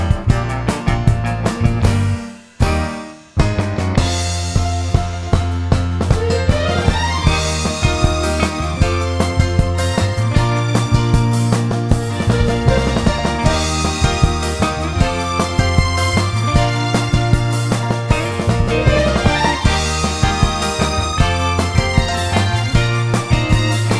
no Backing Vocals Soundtracks 2:43 Buy £1.50